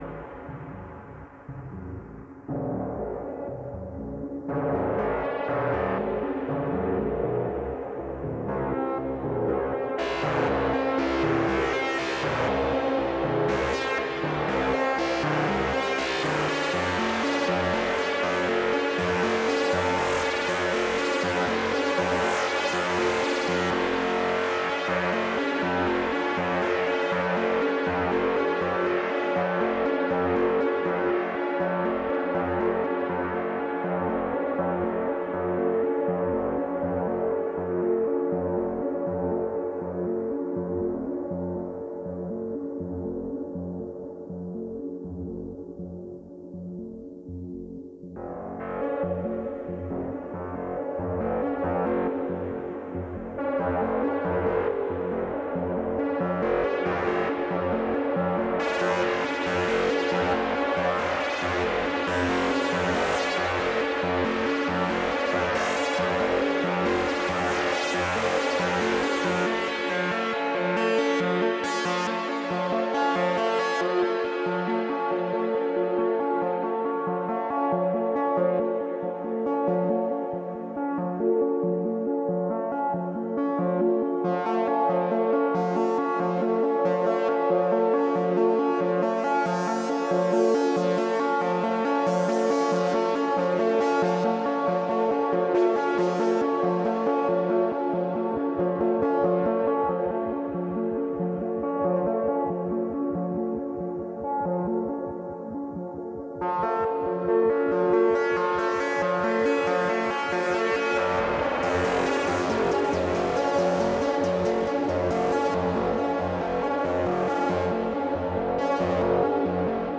Malevolent VCOs into Shakmat ringmod